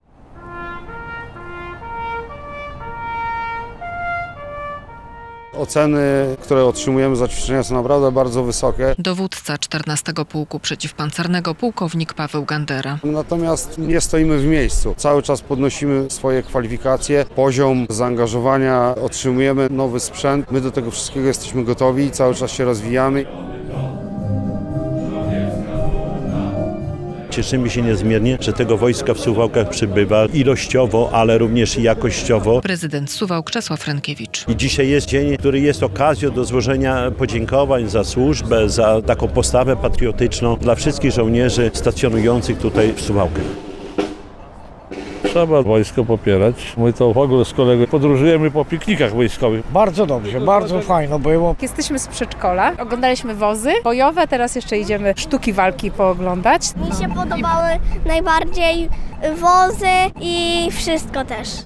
Suwalscy żołnierze uczcili w piątek (30.05) święto swojego pułku. Były awanse, odznaczenia, defilada wojskowa i przejazd Rosomaków.